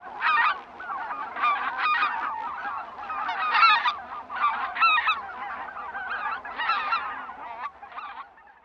マガン｜日本の鳥百科｜サントリーの愛鳥活動
「日本の鳥百科」マガンの紹介です（鳴き声あり）。翼を広げると1.4mほどになる大型の水鳥です。